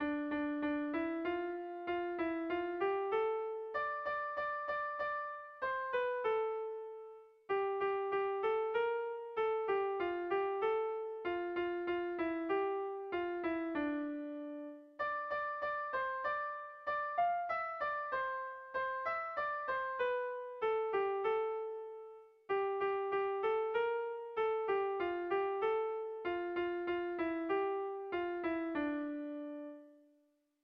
Melodías de bertsos - Ver ficha   Más información sobre esta sección
Zortziko handia (hg) / Lau puntuko handia (ip)
ABDB